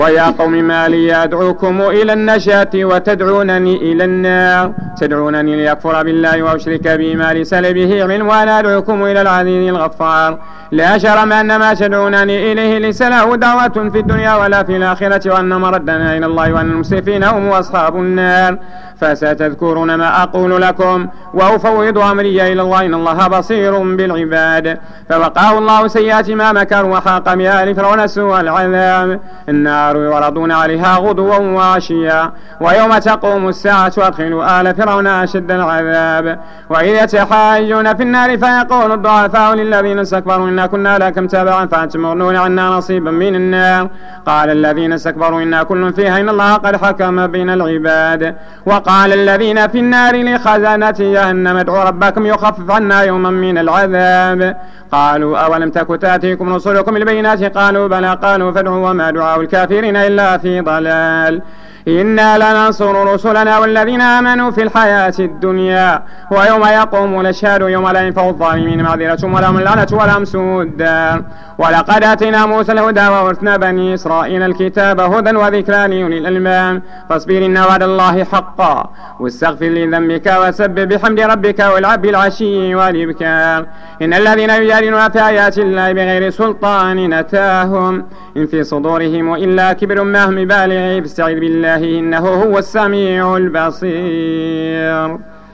صلاة التراويح رمضان 1431/2010 بمسجد ابي بكر الصديق ف الزوى